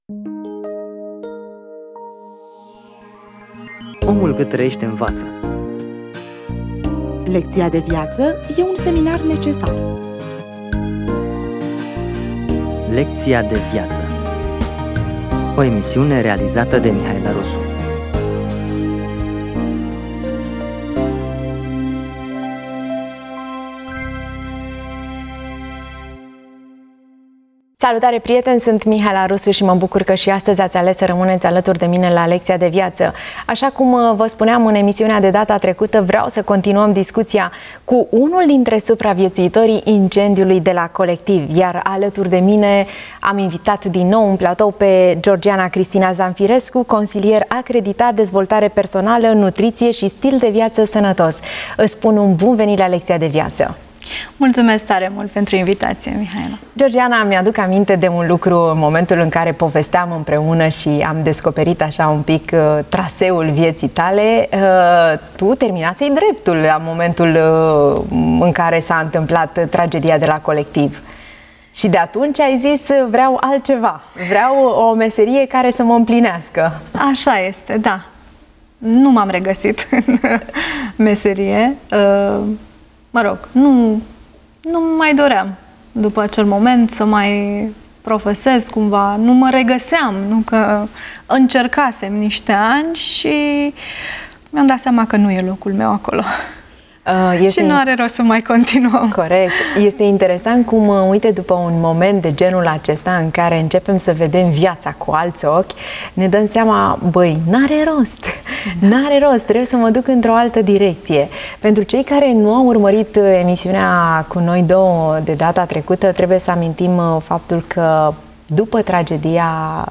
De vorba cu un supravietuitor Colectiv (2)